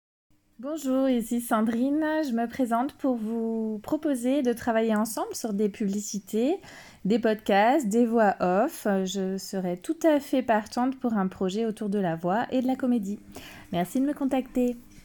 Voix off
Proposition voix off et publicité